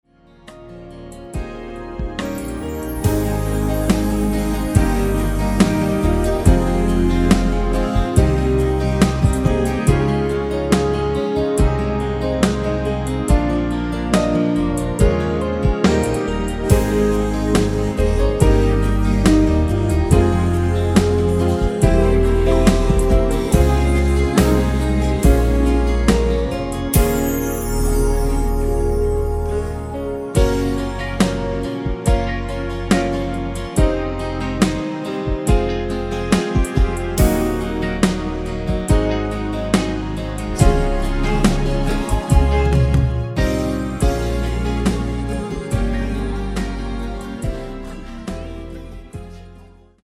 Ab
◈ 곡명 옆 (-1)은 반음 내림, (+1)은 반음 올림 입니다.
앞부분30초, 뒷부분30초씩 편집해서 올려 드리고 있습니다.
위처럼 미리듣기를 만들어서 그렇습니다.